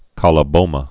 (kŏlə-bōmə)